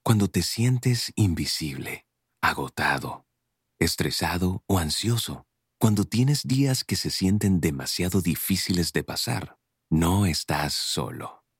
Male
From commercials to narration, audiobooks, podcasts, and radio/TV voiceovers, I offer a neutral Spanish accent that fits a wide range of styles. My voice is smooth, professional, and easy to connect with.
Radio Commercials